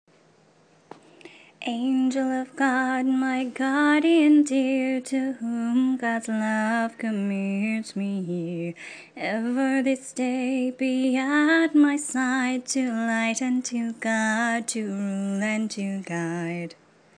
So here’s a couple of prayers in tune form that might just be a hit in your house!
Melody: Ride a Cock Horse